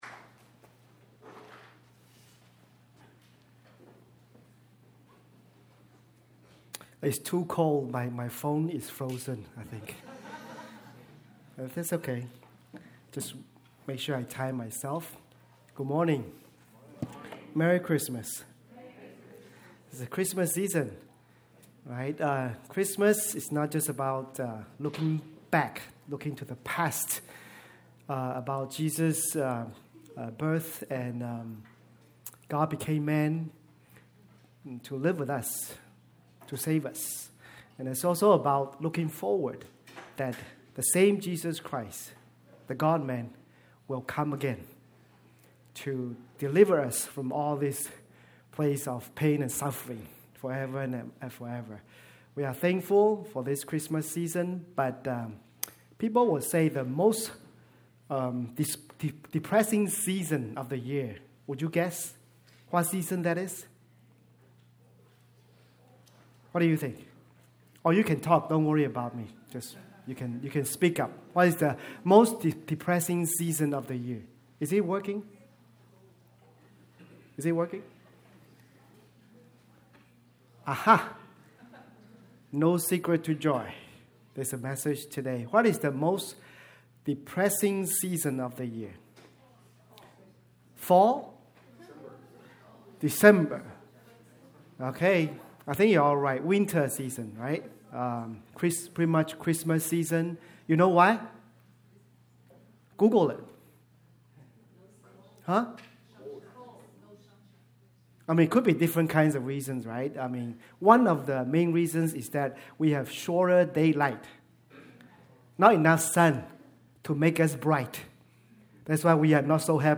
A message from the series "Christmas."